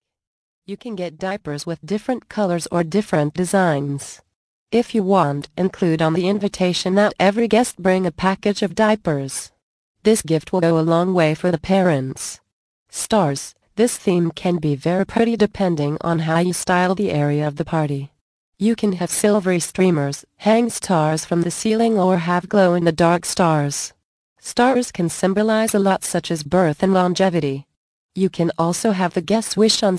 The Ultimate Baby Shower Guide mp3 audio book Vol. 5